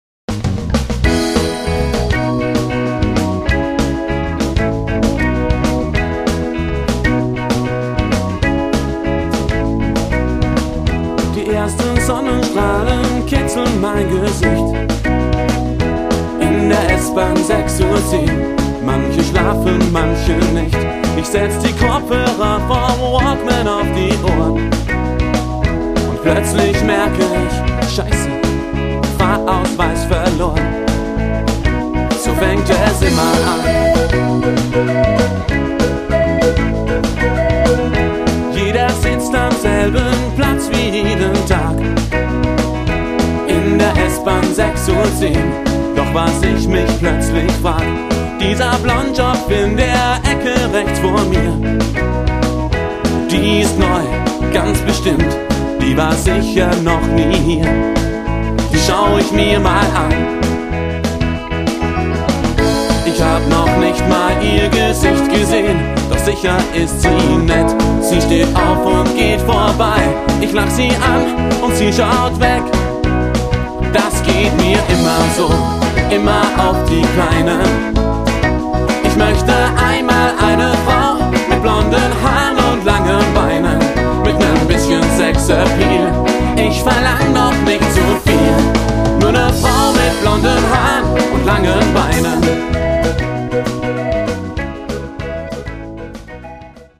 Pop mit deutschen Texten